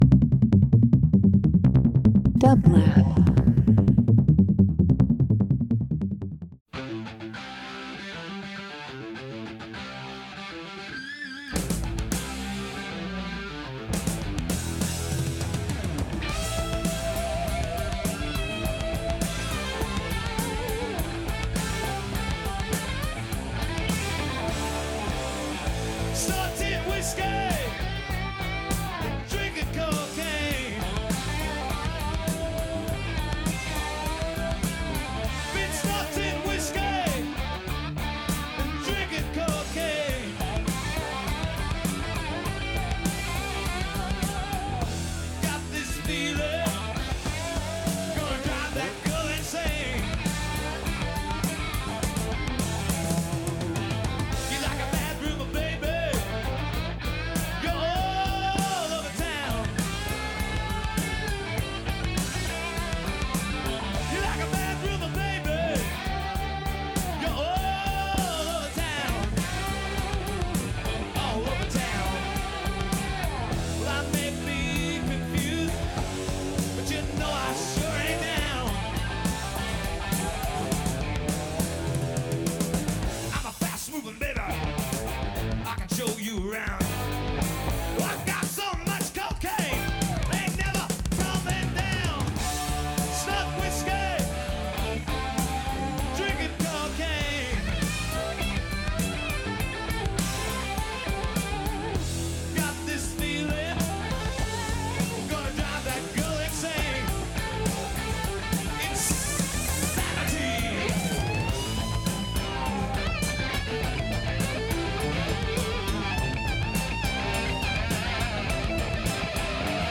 Blues Country Folk